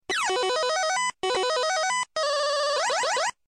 короткие
8-бит